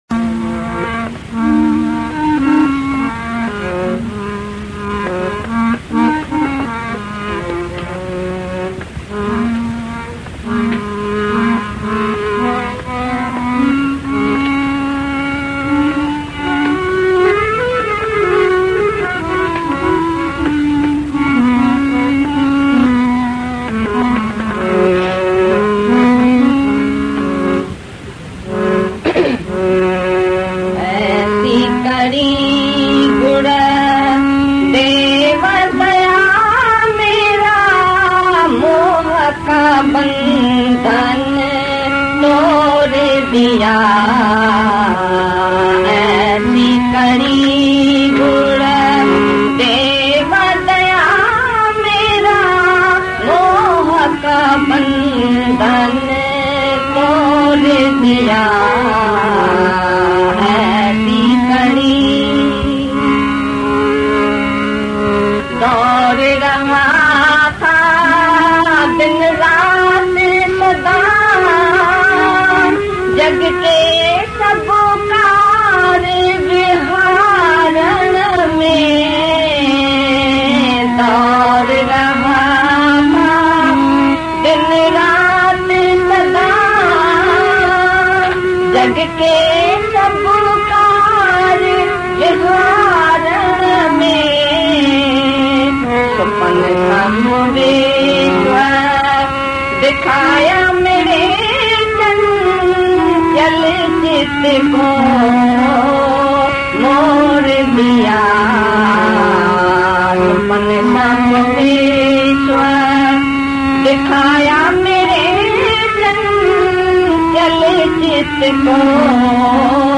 Aisi-Kari-GuruDev-Daya-Mera-Moh-ka-Bandhan-Bhajan.mp3